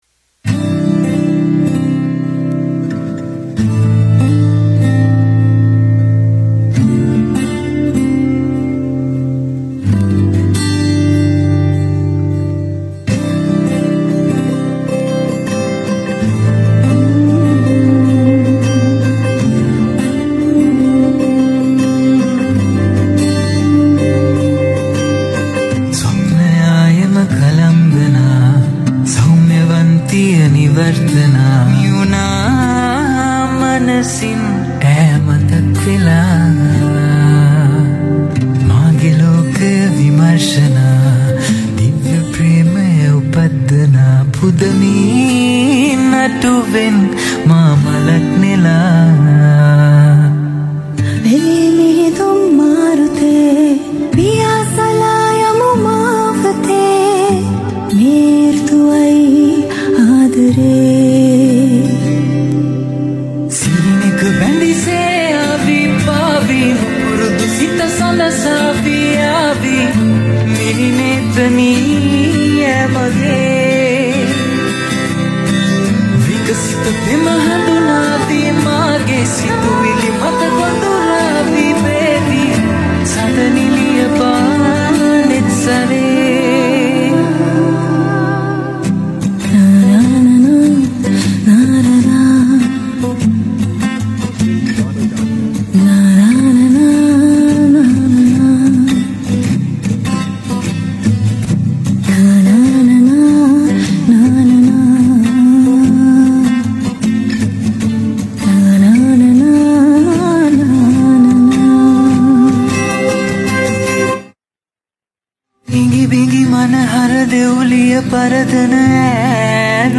Vocals
Guitars